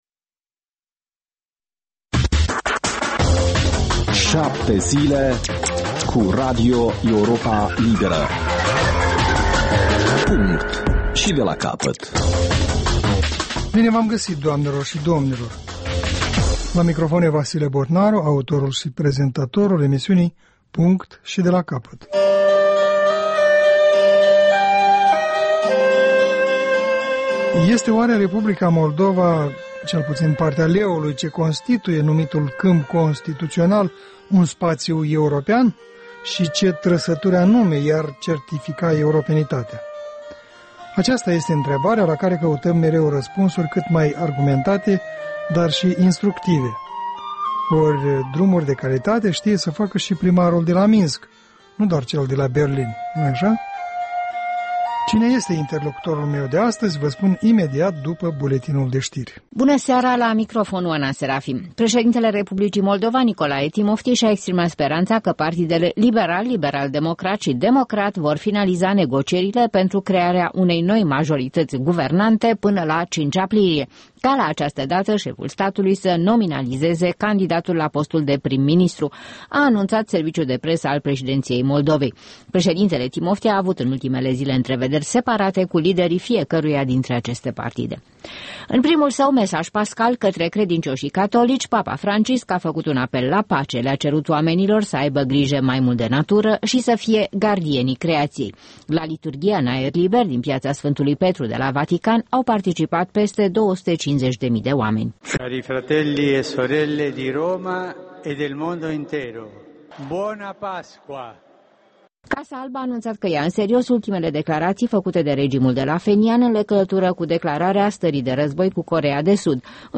O emisiune în reluare cu un buletin de ştiri actualizat, emisiunea se poate asculta şi pe unde scurte